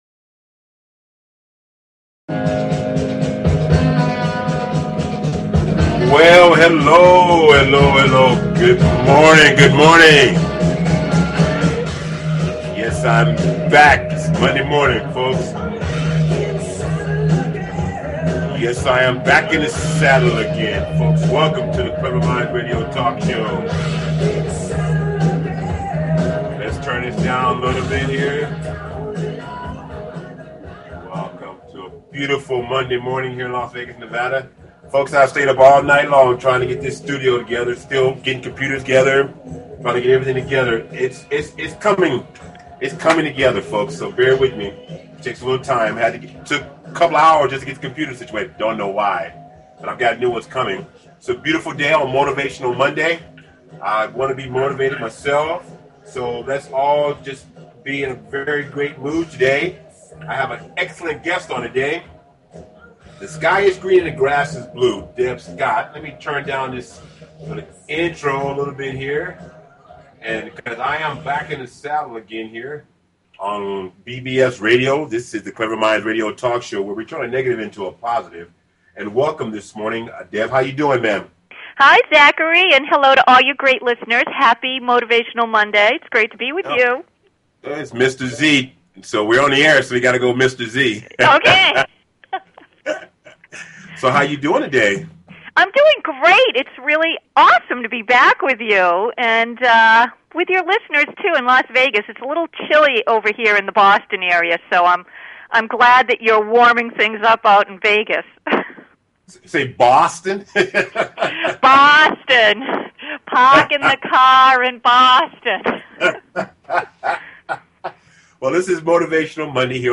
Talk Show Episode, Audio Podcast, Cleverminds and Courtesy of BBS Radio on , show guests , about , categorized as